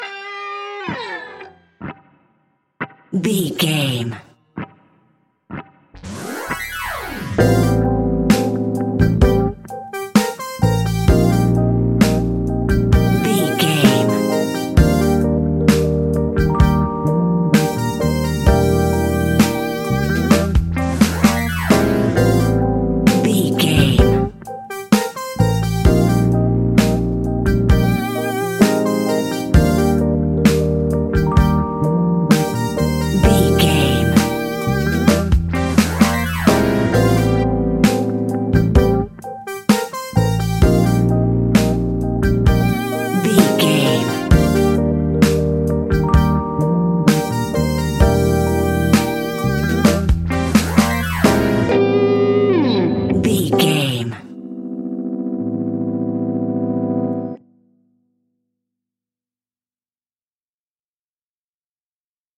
Fast paced
Uplifting
Ionian/Major
hip hop